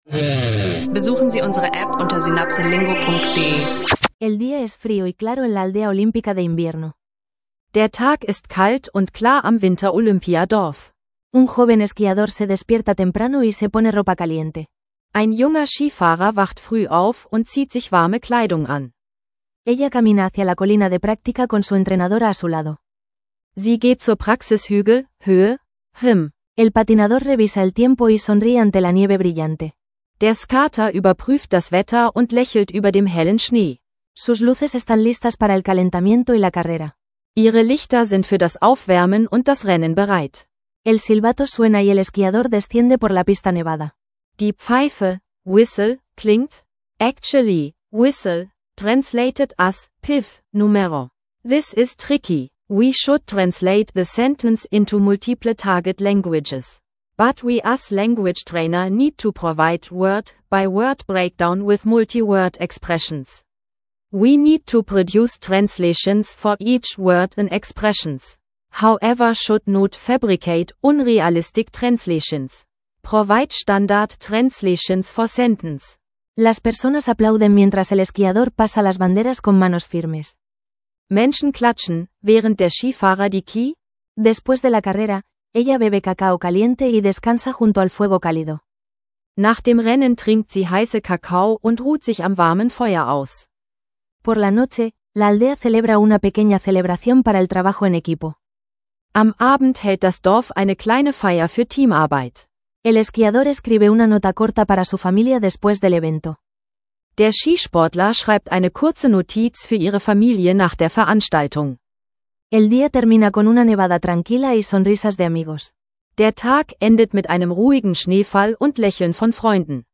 Lerne Spanisch mit realen Geschichten über Wintersport, Migration und Alltag – inklusive Vokabeln, Phrasen und praxisnahem Dialog.